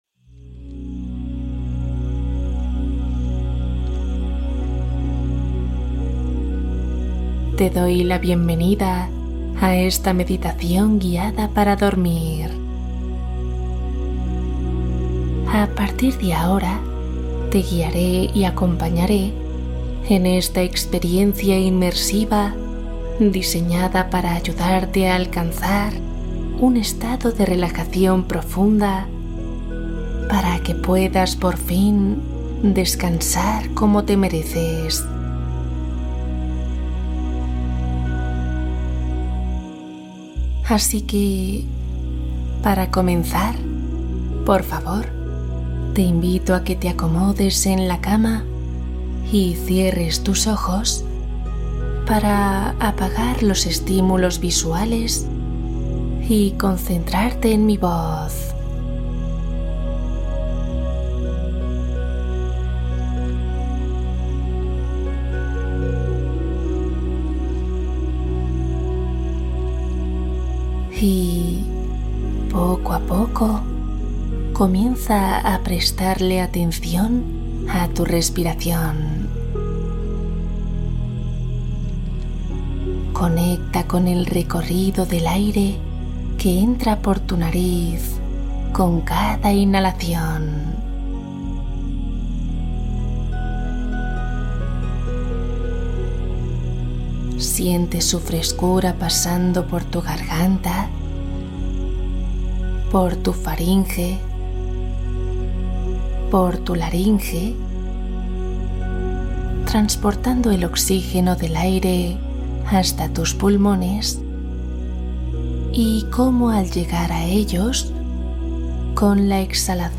Esta noche duerme profundamente Meditación guiada para descanso reparador